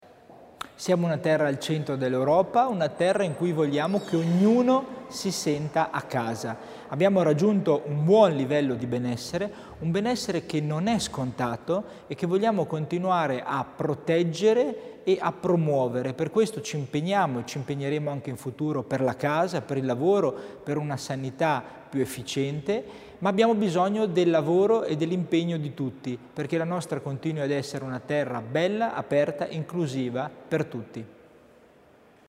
Il Vicepresidente Tommasini spiega le strategie future
Evento conclusivo di fine legislatura della Giunta provinciale.